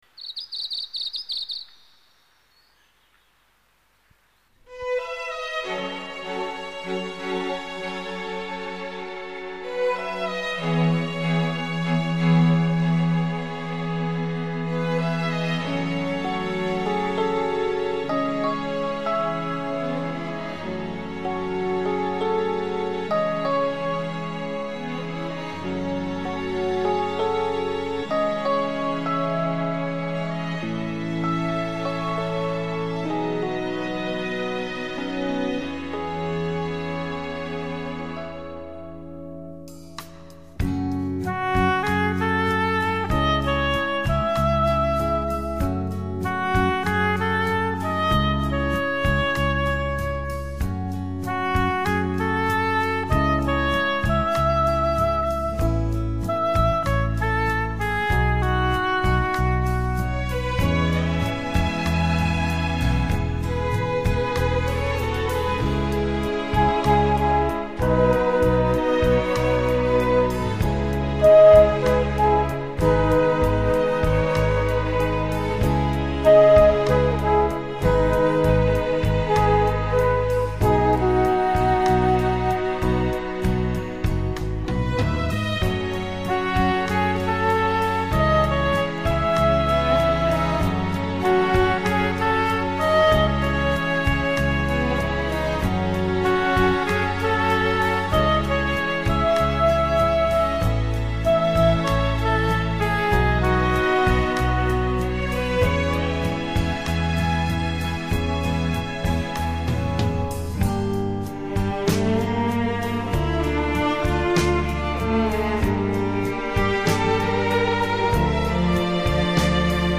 音乐类别: 纯音乐,New Age, Various